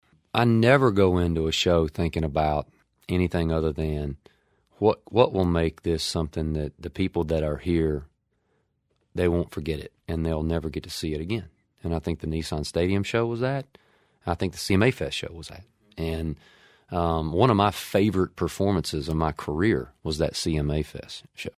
Audio / Eric Church says two of his most memorable shows this year were his sold-out Nissan Stadium show and his performance during CMA Music Fest.